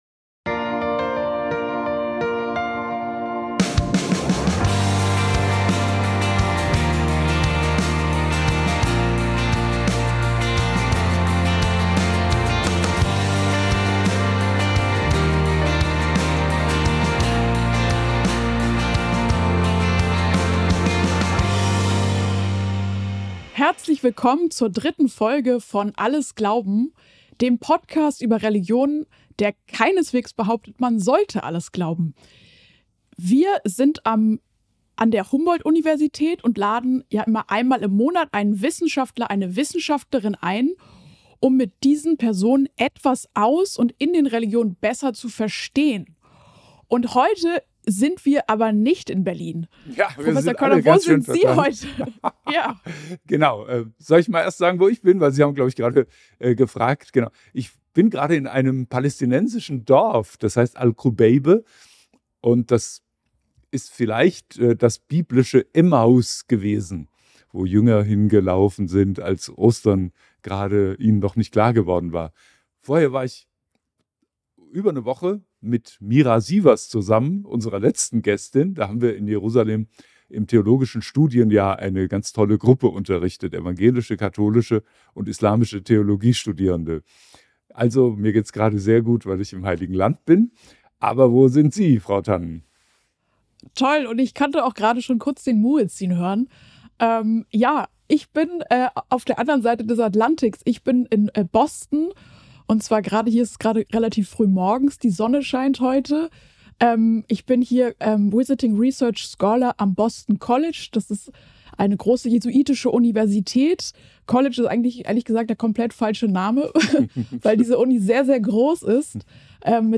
Ein Gespräch über Rituale als Identitätsstifter und Erinnerungsgemeinschaft, persönlichen Mut und die tägliche Herausforderung von Jüdinnen und Juden in Deutschland im öffentlichen Raum sichtbar religiös zu sein.